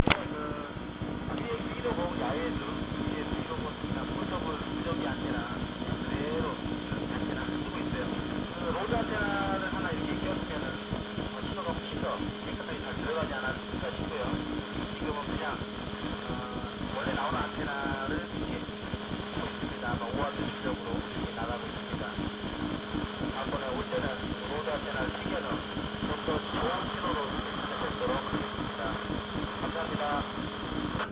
도봉산/P교신음성